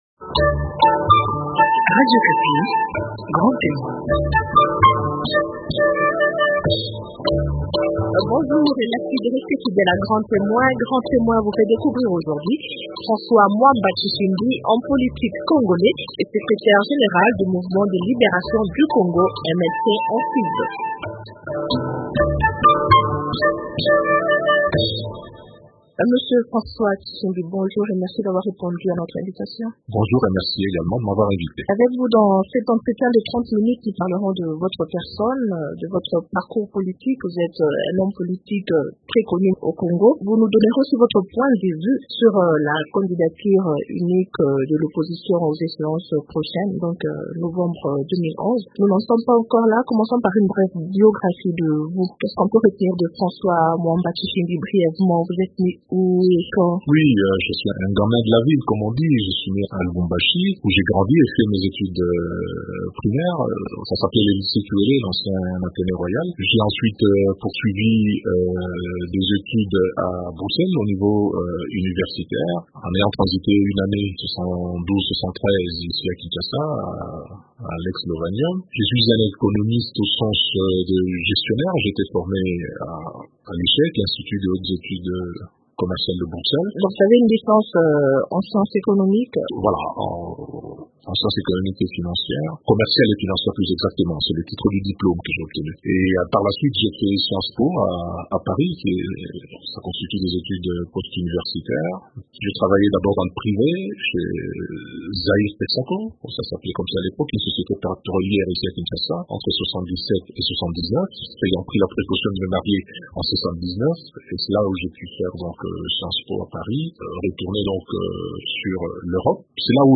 Un des acteurs politiques congolais en vue, François Mwamba Tshishimbi est actuellement député national et président du groupe parlementaire de l’opposition et de son parti, le MLC dont il est le secrétaire général.